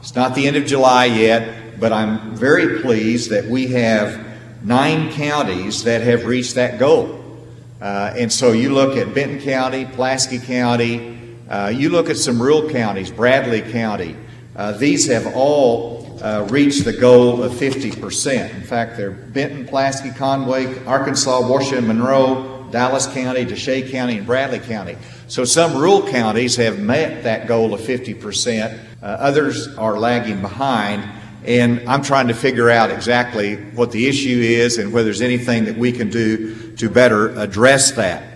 Gov. Asa Hutchinson brought his “Community COVID Conversations” event to Mountain Home Monday, holding an hour-long town hall-style event inside the Vada Sheid Community Development Center on the campus of Arkansas State University-Mountain Home.